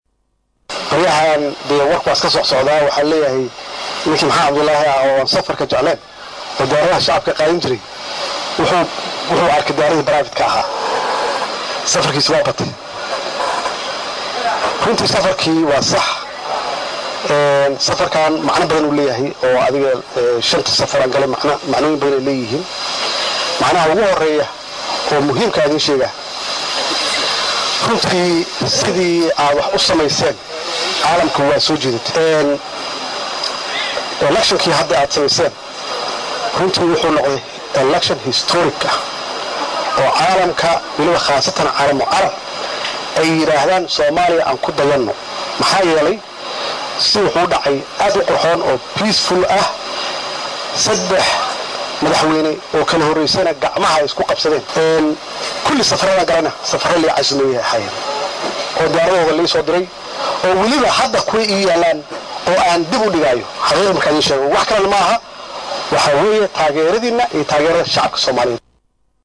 DHAGAYSO CODKA: Madaxweyne Farmaajo oo ka hadlay sababta ay Safaradiisa u bateen | Goobsan Media Inc